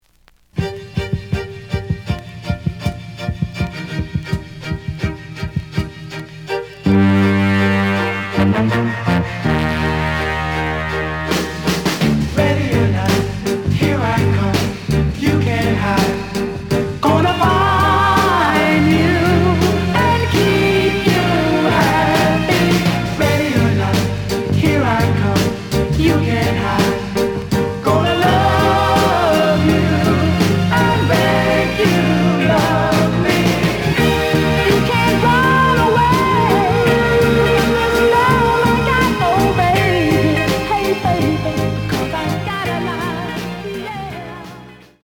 The audio sample is recorded from the actual item.
●Genre: Soul, 60's Soul
Edge warp.